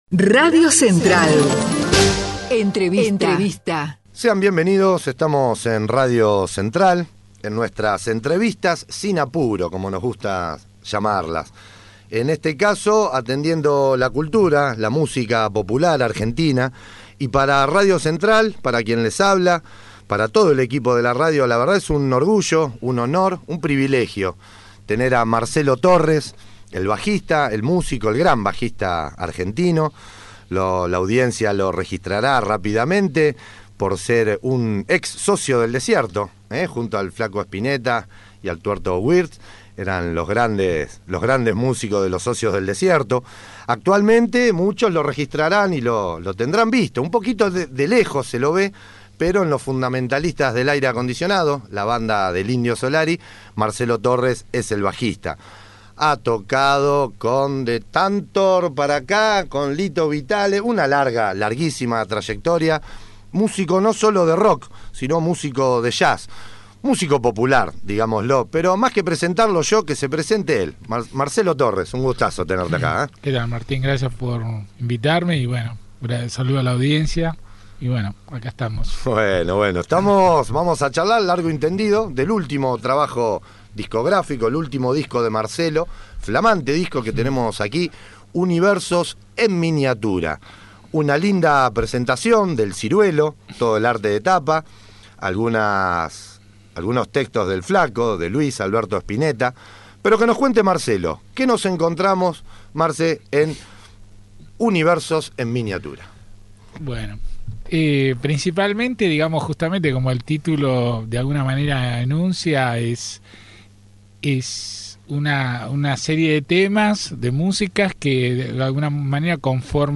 El talentoso bajista también repasa su trayectoria junto a Luis Alberto Spinetta, Carlos "Indio" Solari, Lito Vitale, Adriana Varela, Castiñeira de Dios, entre otros grandes artistas.